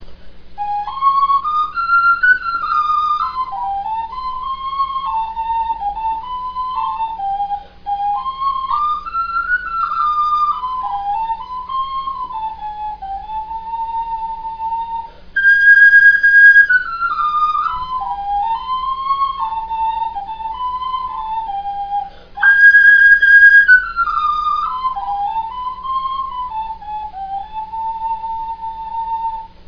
Dragon Ocarina Clay Flutes